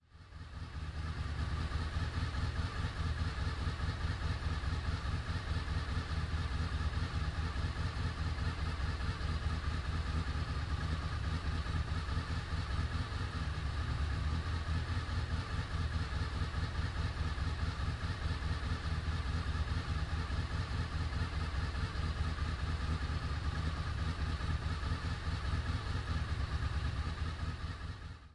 15开始的摩托车